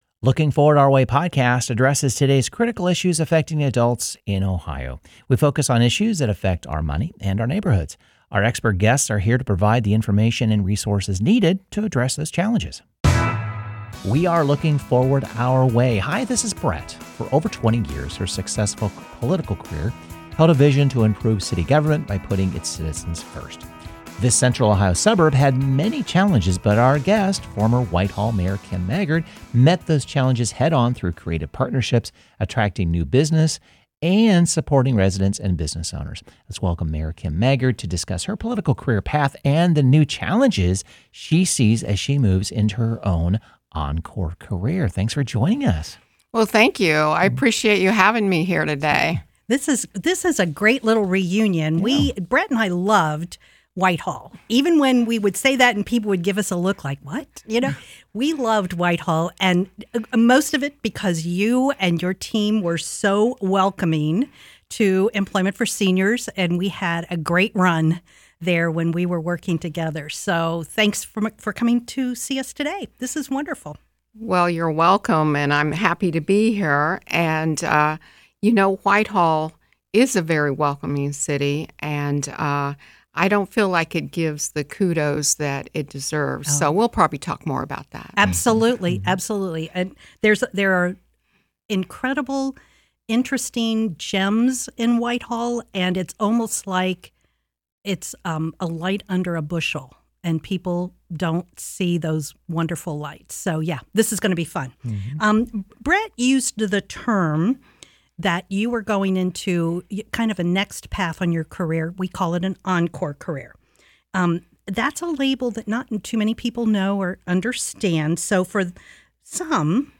We sit down with former Whitehall Mayor Kim Maggard for an engaging and insightful conversation about her impressive journey in public service, community leadership, and what lies ahead in her own encore career.